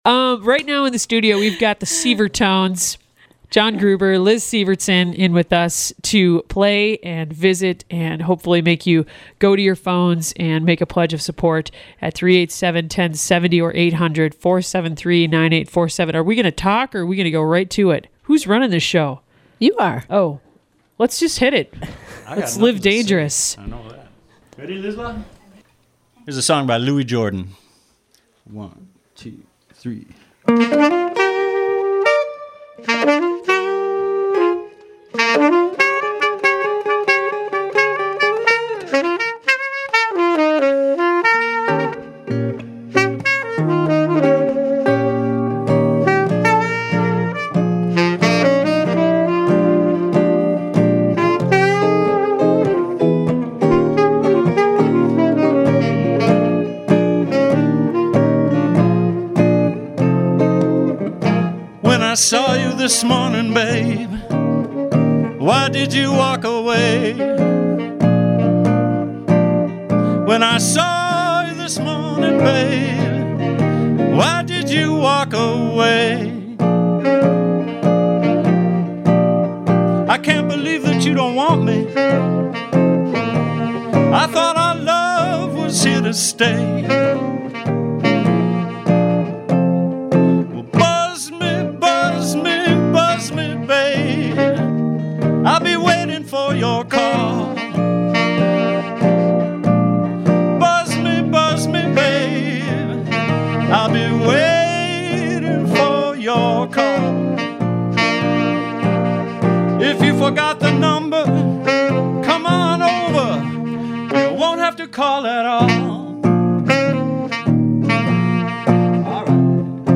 The Si!vertones bring charmingly eclectic repertoire to Studio A
sax & clarinet
guitar & vocals